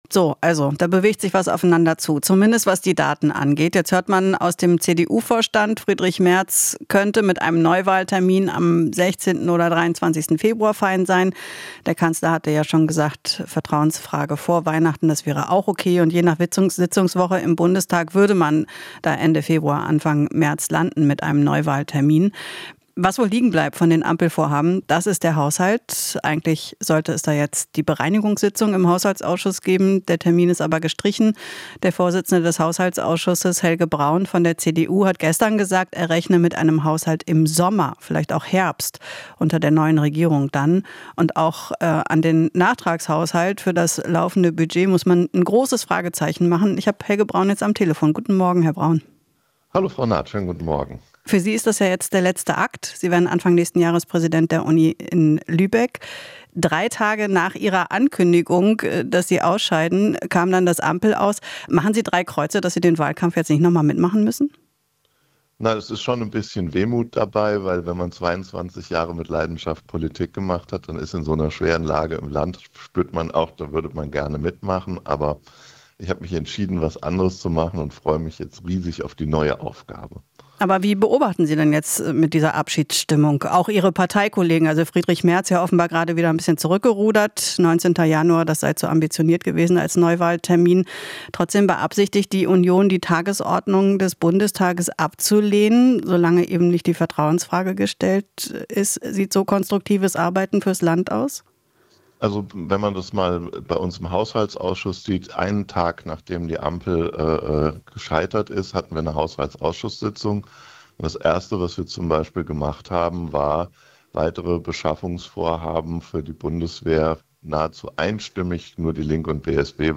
Interview - Braun (CDU): Müssen einen Kassensturz machen